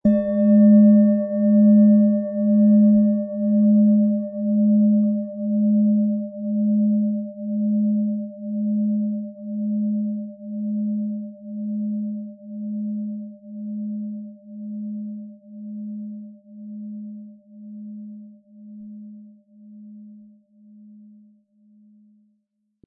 Tibetische Herz- und Bauch-Klangschale, Ø 16,7 cm, 700-800 Gramm, mit Klöppel
Lieferung inklusive passendem Klöppel, der gut zur Klangschale passt und diese sehr schön und wohlklingend ertönen lässt.
SchalenformBihar
MaterialBronze